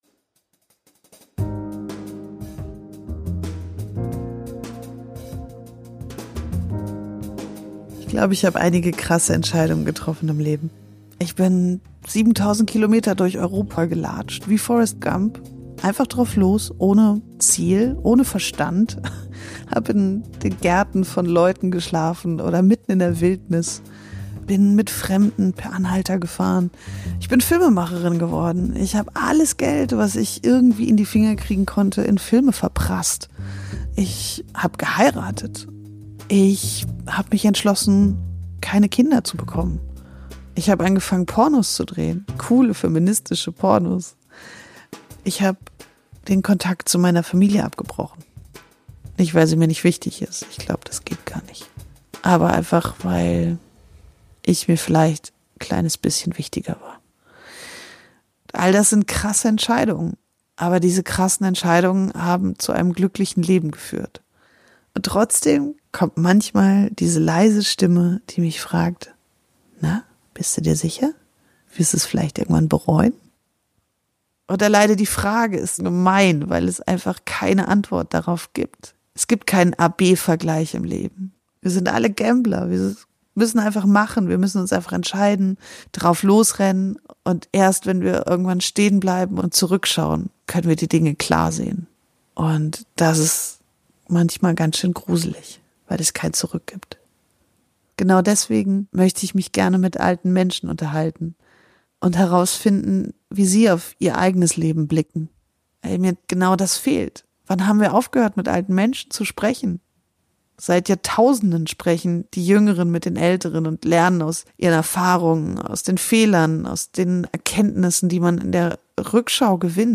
Trailer "Nichts bleibt beim Alten"
Alte Menschen erzählen, was sie wirklich bewegt